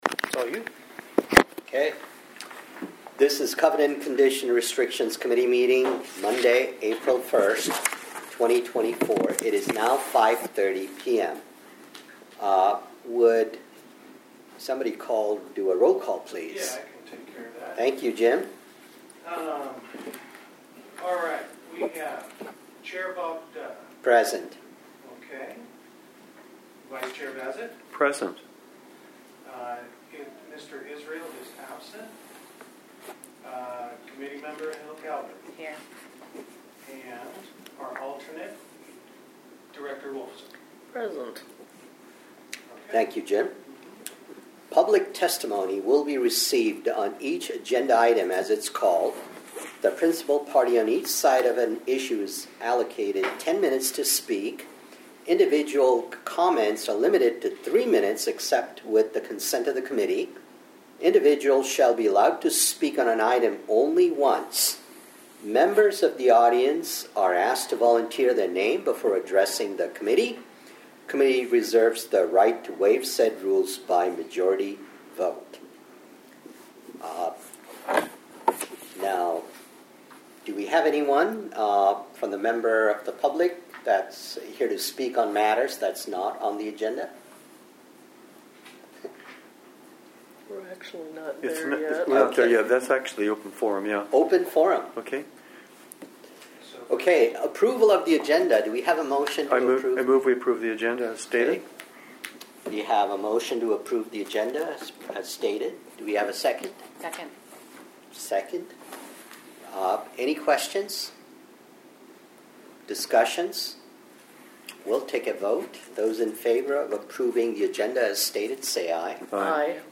Covenants, Conditions, and Restrictions (CC&R) Committee Meeting